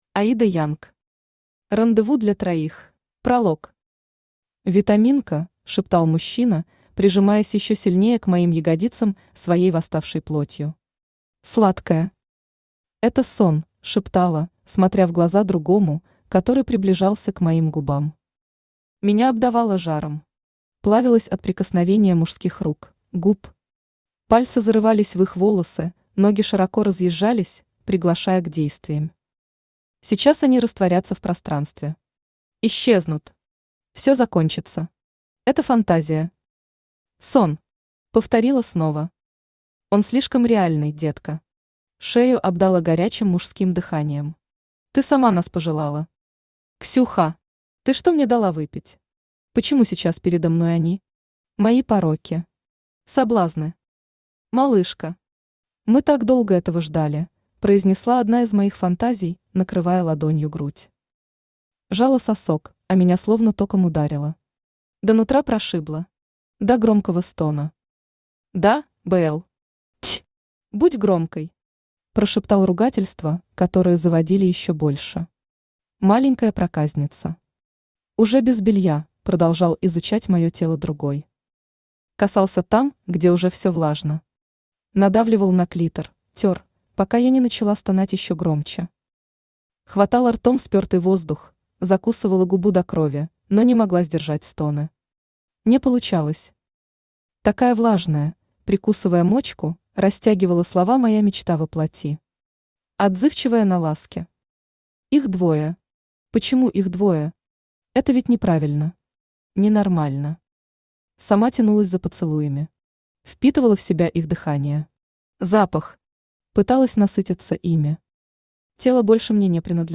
Аудиокнига Рандеву для троих | Библиотека аудиокниг
Aудиокнига Рандеву для троих Автор Аида Янг Читает аудиокнигу Авточтец ЛитРес.